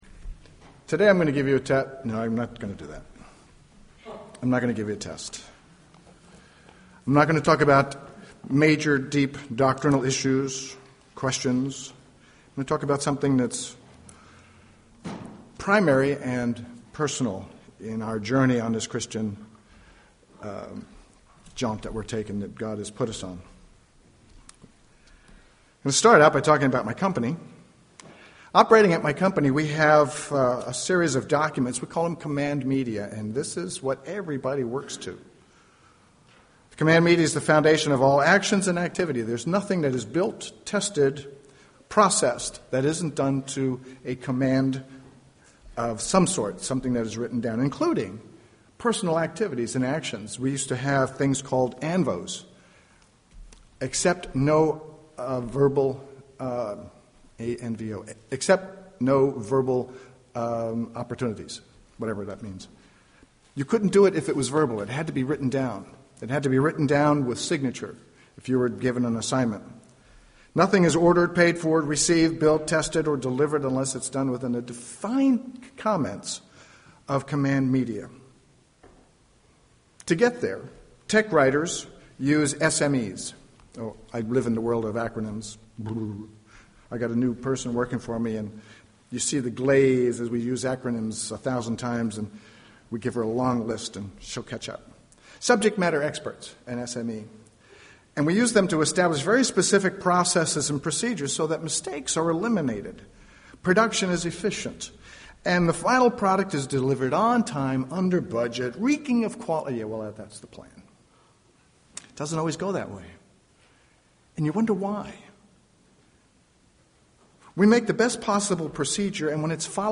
Given in San Jose, CA